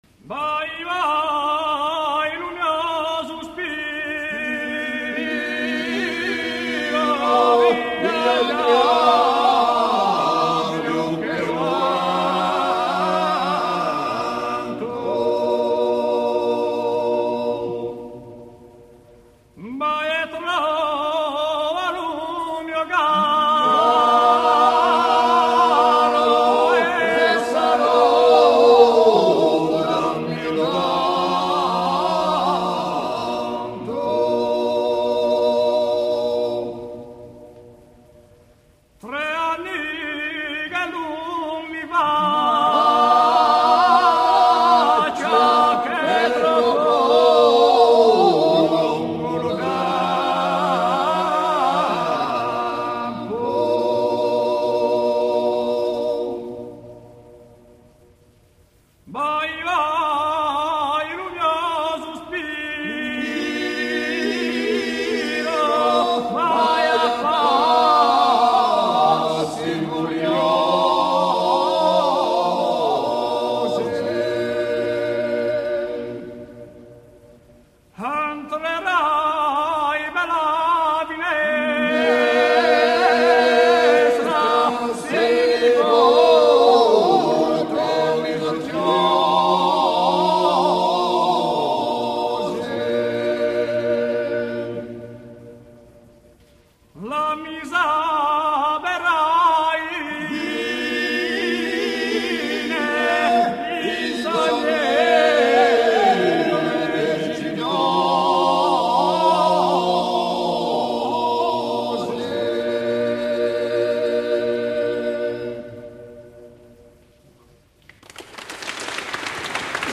:D Да, вот, например, корсиканская песенка с характерным названием "Vai vai":